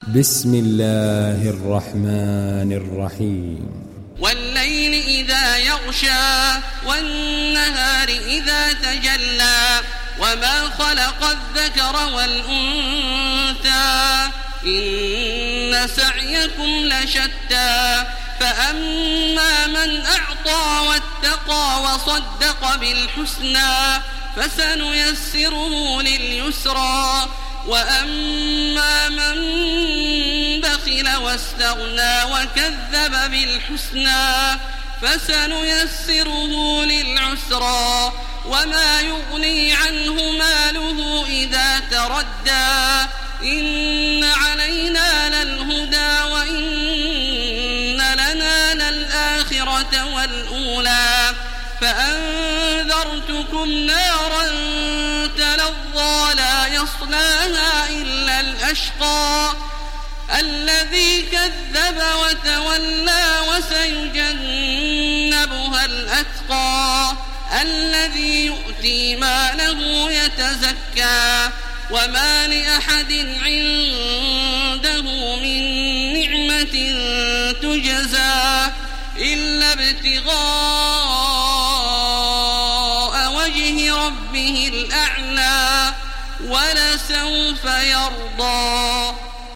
دانلود سوره الليل mp3 تراويح الحرم المكي 1430 روایت حفص از عاصم, قرآن را دانلود کنید و گوش کن mp3 ، لینک مستقیم کامل
دانلود سوره الليل تراويح الحرم المكي 1430